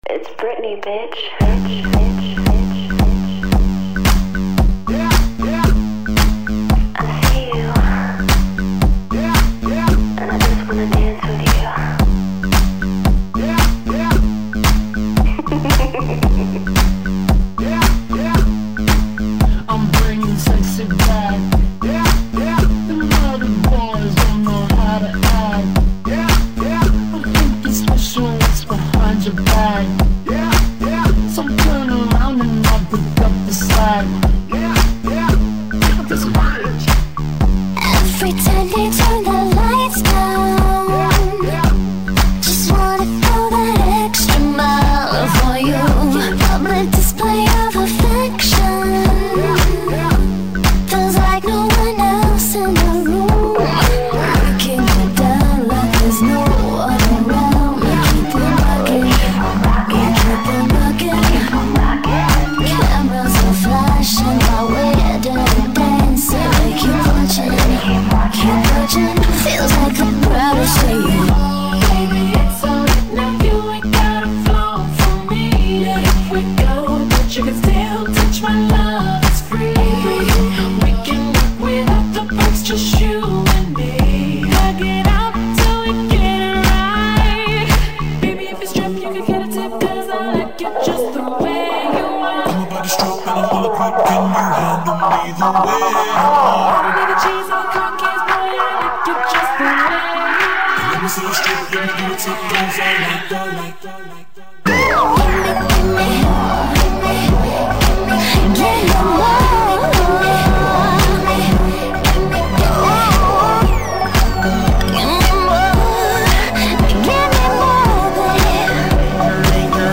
классная темка клубная